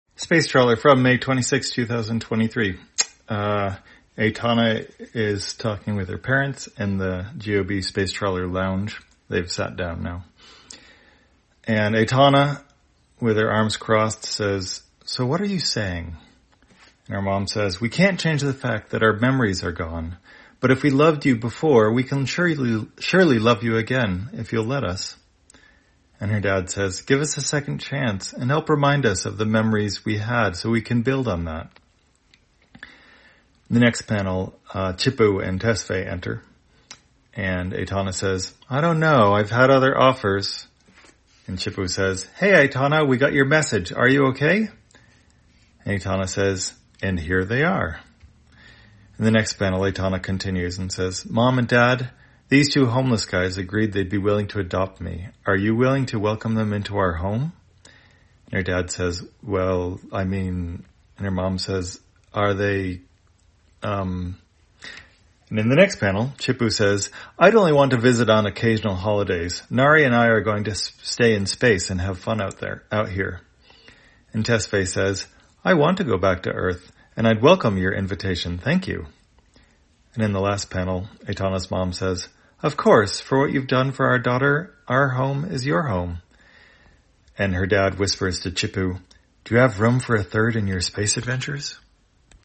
Spacetrawler, audio version For the blind or visually impaired, May 26, 2023.